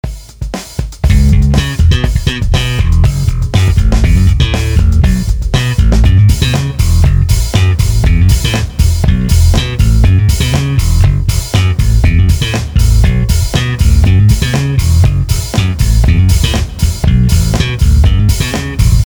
Vous l'aurez remarquez, je ne suis pas une fine lame du slap.
un Bass PodXt
une Jap de 89. c'est des micro US. et c'est de l'aulne, je pense.
Son_Precision_Slap.MP3